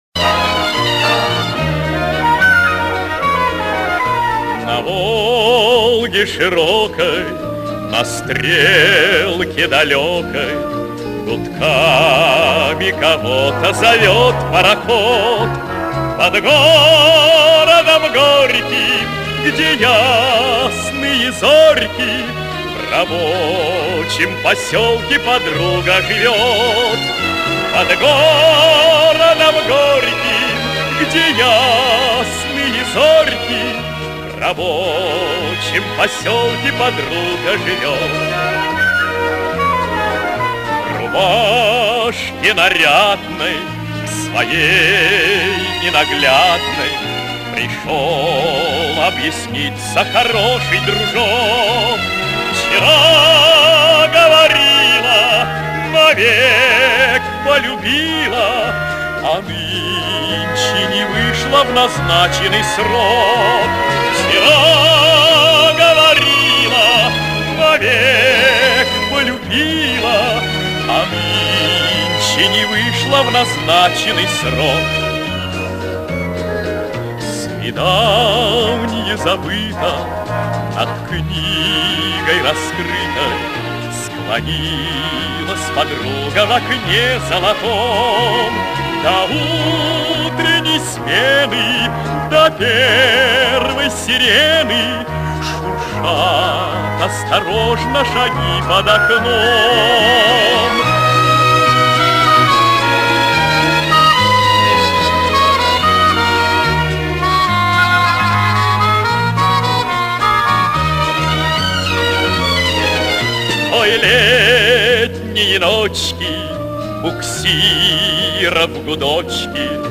вытянул дорожку из видео